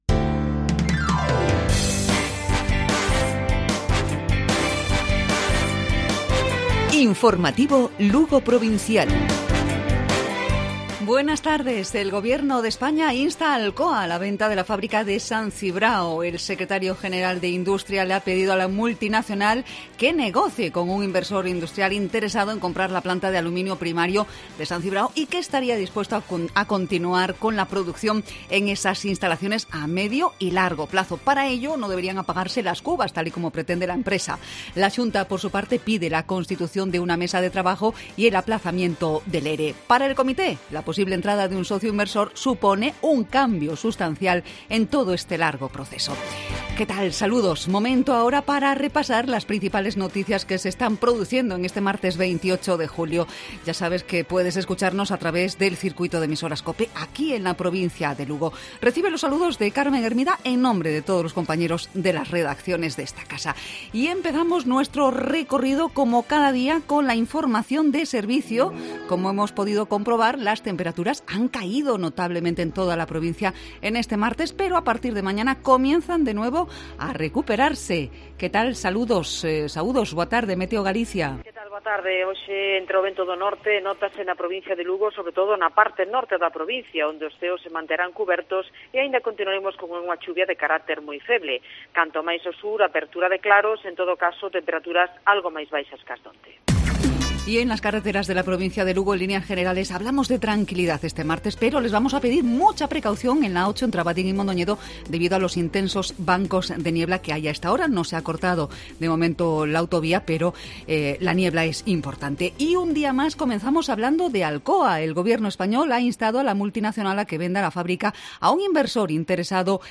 Informativo Provincial Cope Lugo. Martes, 28 de julio. 12:50-13:00 horas